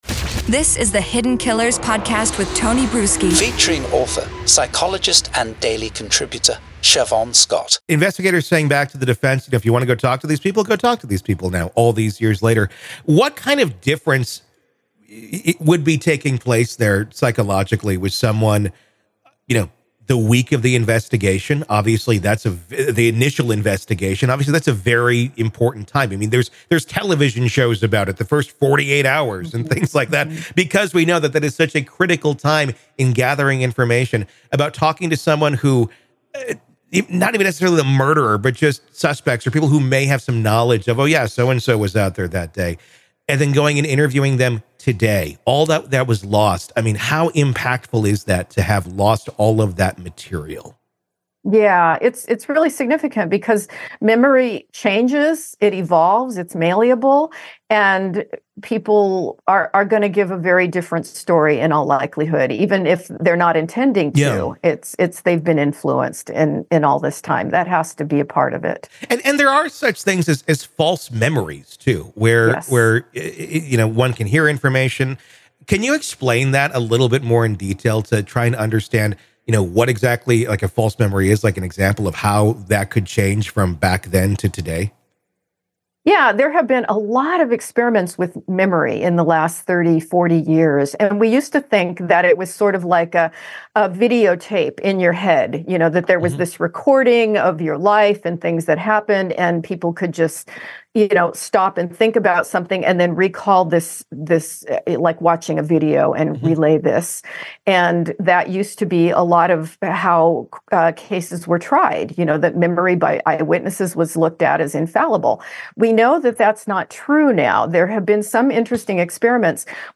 The conversation covers the broader implications of these psychological insights for the justice system, particularly in cases where evidence and testimonies are lost or not recorded, leading to challenges in ensuring fair trials and accurate verdicts.